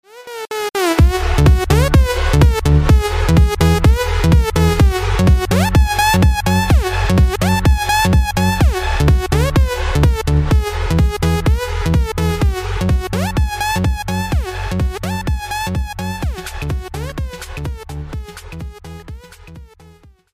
Long Drop Alert Download
long-drop-alert_KS9QQFs.mp3